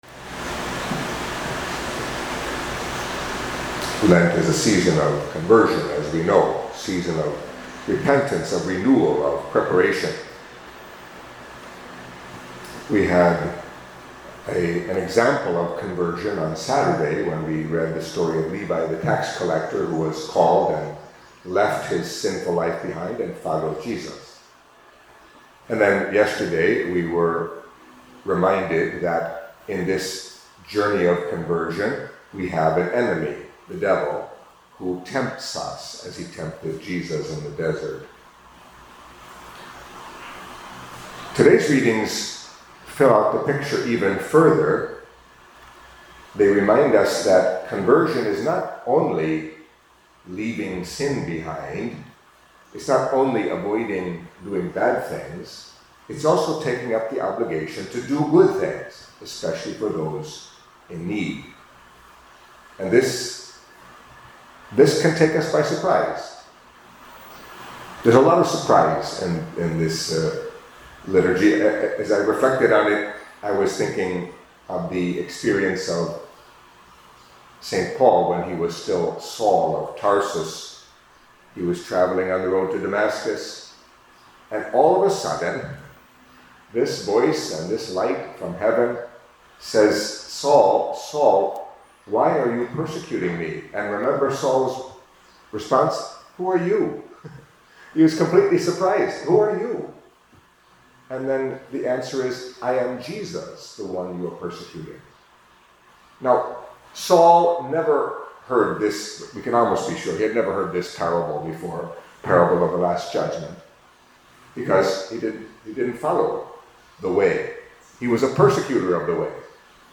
Catholic Mass homily for Monday of the First Week of Lent